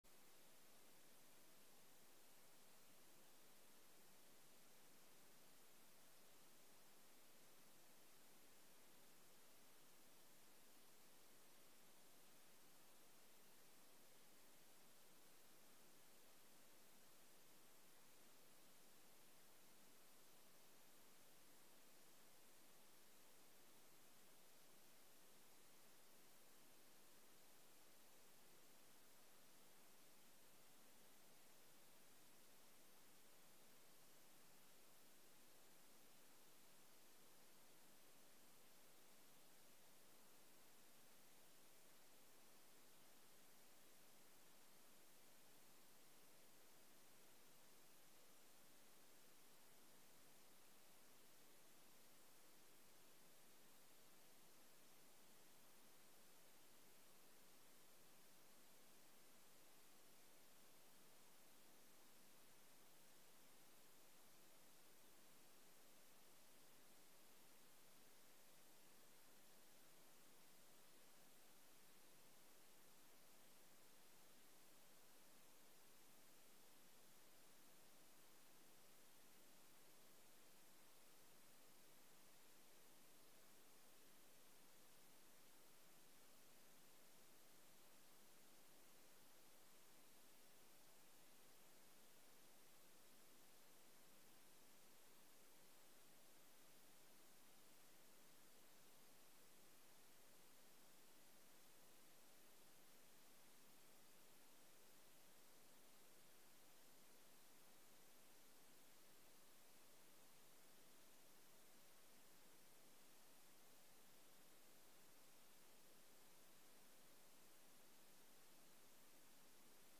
De vergadering vindt plaats in het Innovitapark met een beperkt aantal raadsleden. De publieke tribune is gesloten.